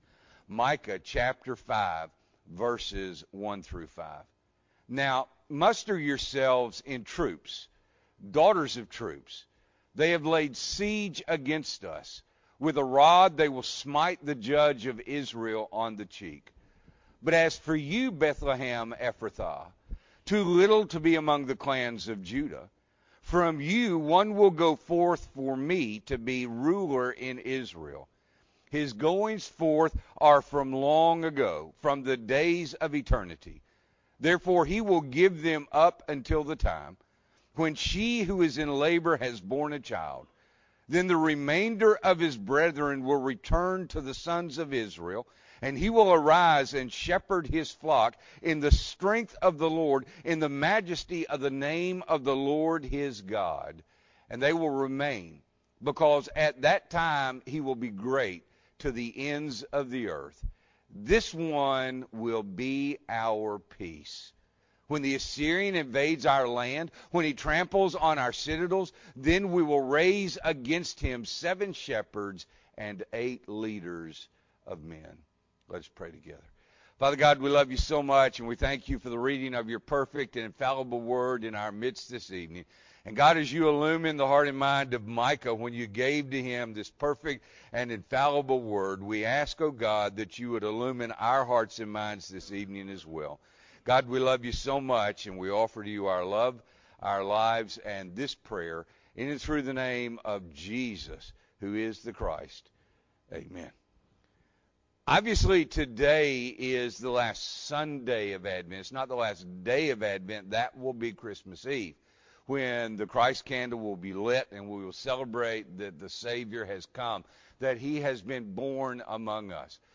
December 19, 2021 – Evening Worship